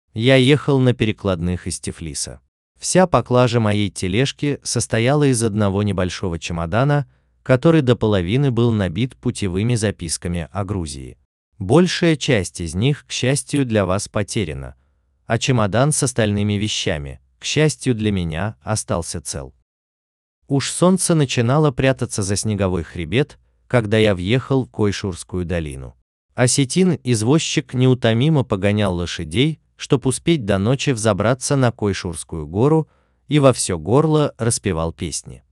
Примеры аудиокниг
Выберите одного из 4-х наших роботизированных дикторов:
Речь робота обладает естественным и выразительным тоном и неизменно высоким качеством